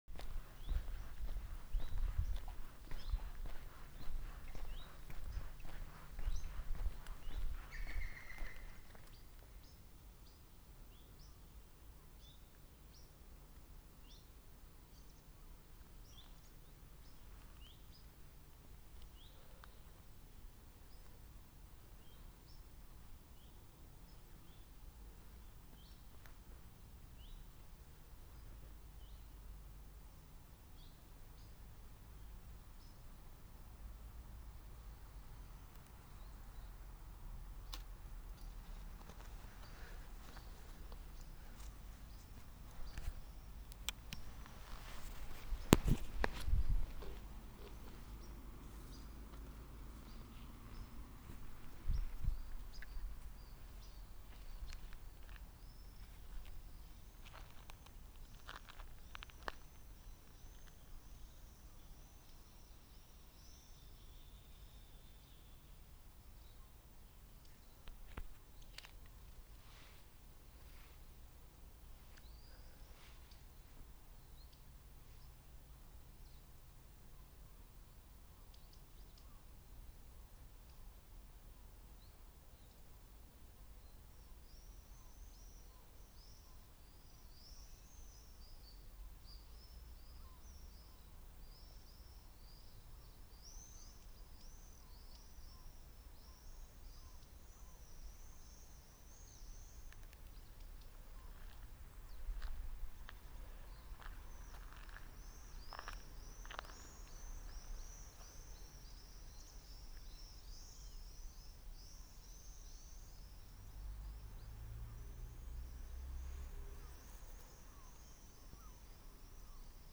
Zaļā dzilna, Picus viridis
Administratīvā teritorijaAlūksnes novads
StatussDzied ligzdošanai piemērotā biotopā (D)
Piezīmes/Dzirdēta vienu reizi (ierakstā 8.sekundē), vizuāli nav novērota, uz provokāciju neatsaucas